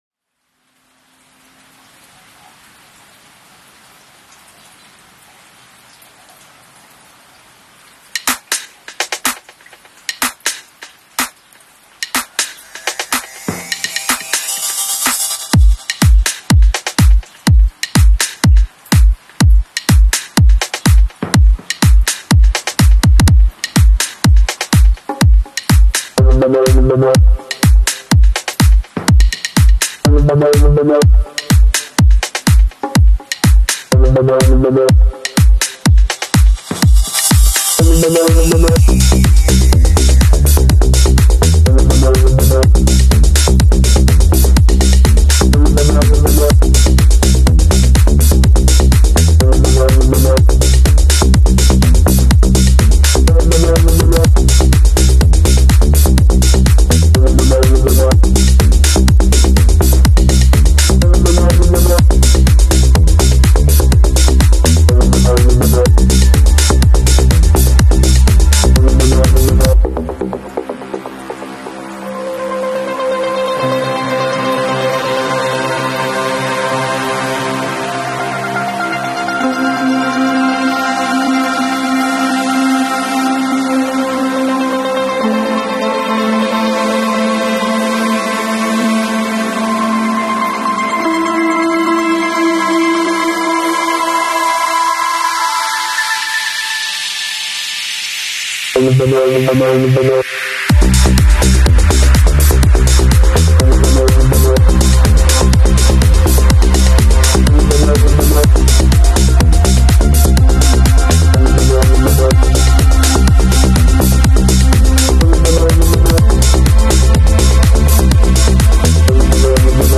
rainfall texture